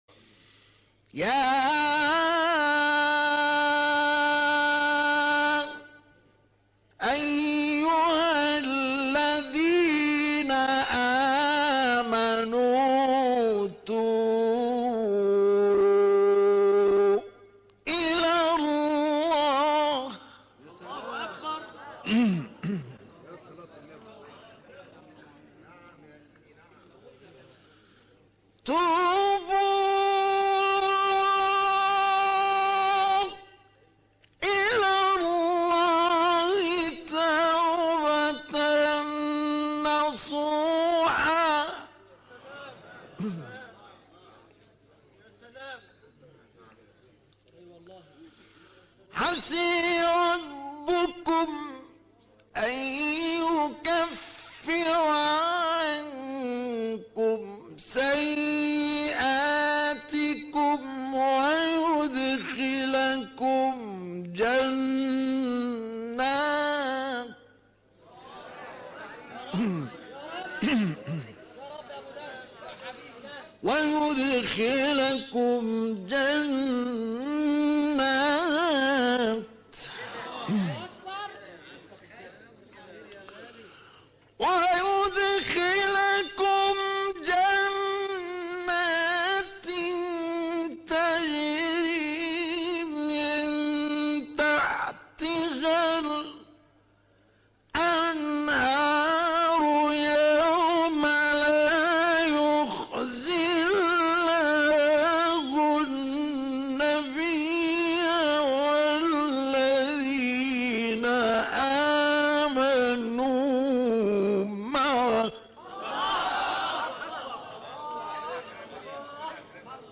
گروه شبکه اجتماعی: تلاوت‌های متفاوت آیه 8 سوره مبارکه تحریم با صوت مصطفی اسماعیل را می‌شنوید.
مقطعی از تلاوت در سال 1962 در محفل ابوالدردار